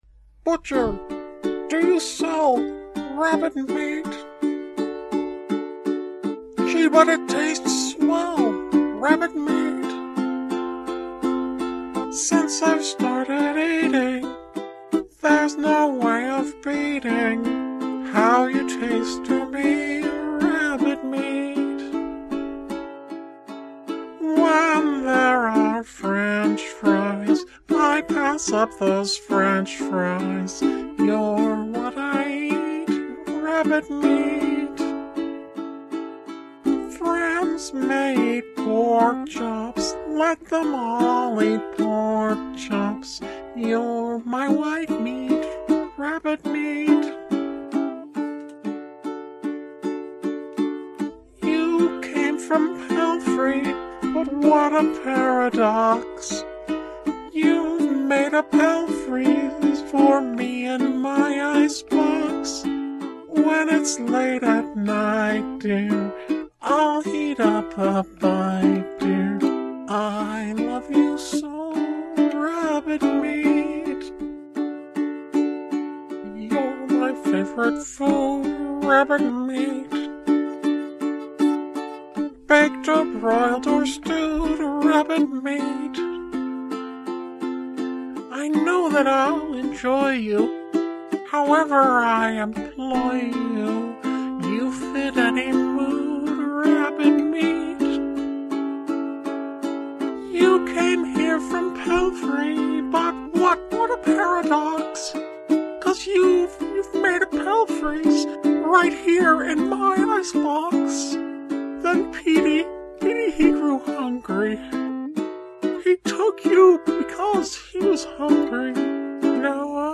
Going through the mysterious box of reel-to-reel tapes I'd earlier uncovered, I picked another one at random.
Now, I'm no music historian, but both the voice and the song sounded somehow familiar to me.